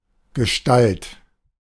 As used in Gestalt psychology, the German word Gestalt (/ɡəˈʃtælt, -ˈʃtɑːlt/ gə-SHTA(H)LT,[4][5] German: [ɡəˈʃtalt]
De-Gestalt.ogg.mp3